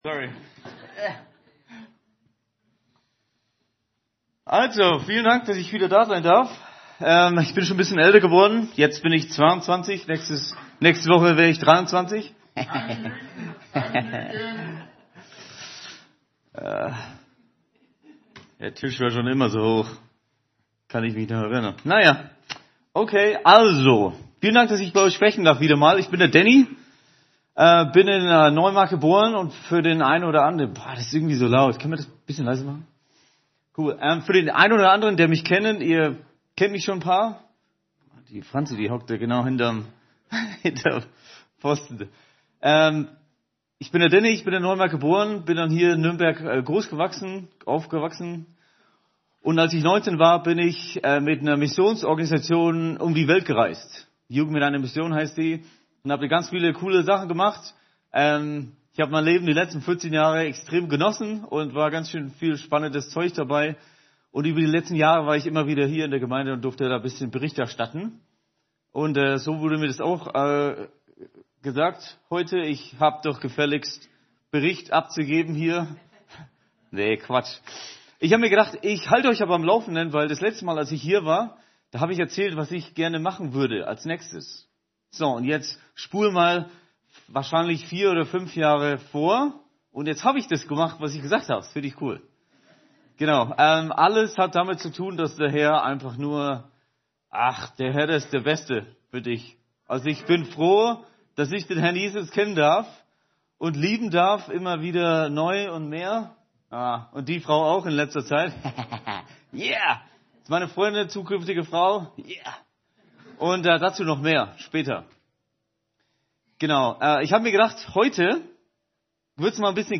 Predigten | Willkommen